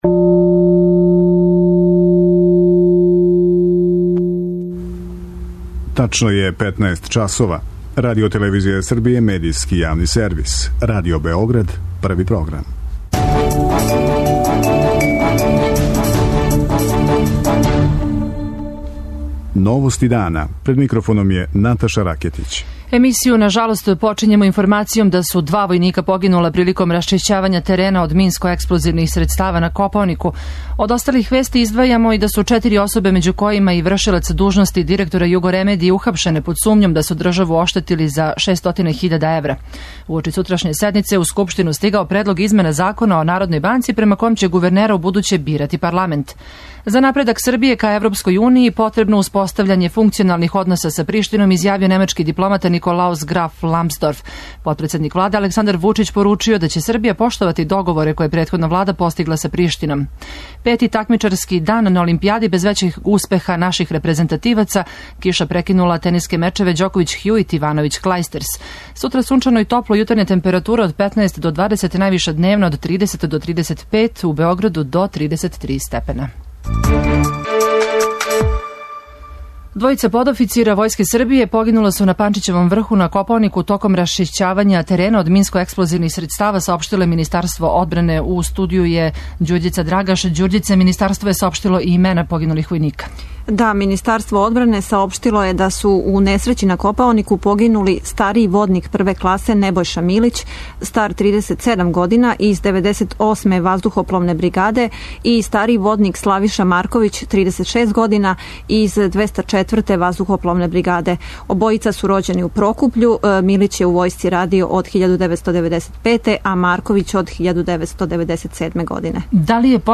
Мининистар правде и државне управе Никола Селаковић изјавио је за Радио Београд 1 да је неопходно направити свеобухватну анализу стања у правосуђу, а када она буде завршена, биће организован најшири друштвени дијалог о томе.